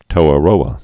(tōə-rōə)